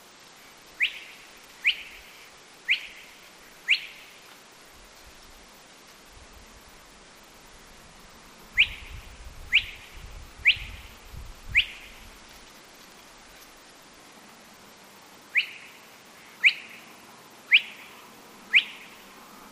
погоныш, Porzana porzana
Ziņotāja saglabāts vietas nosaukumspalienes pļava
СтатусСлышен голос, крики